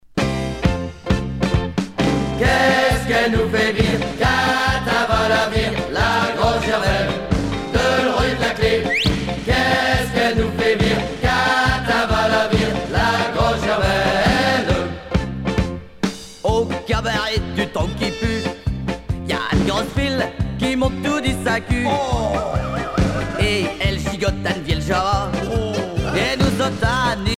danse : marche ;
Genre strophique
Pièce musicale éditée